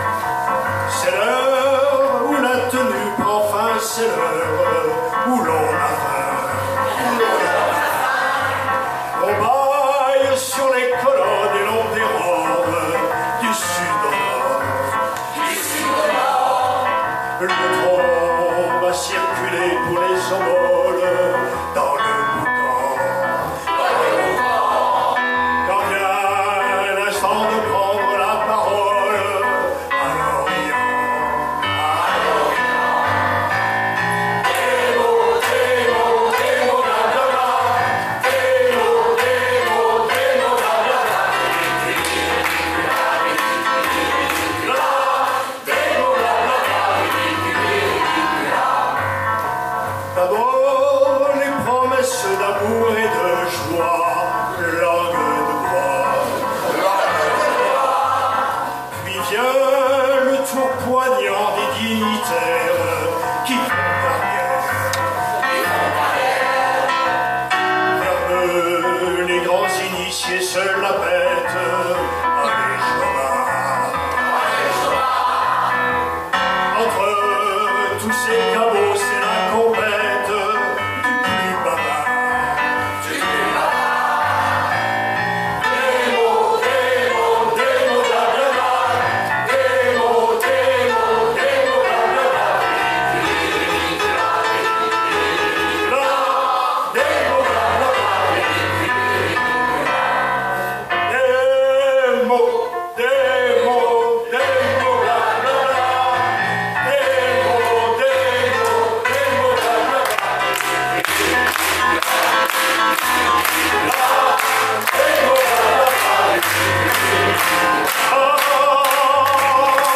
Chansons maçonneuses                                Suivante
Enregistrement public Festival 2023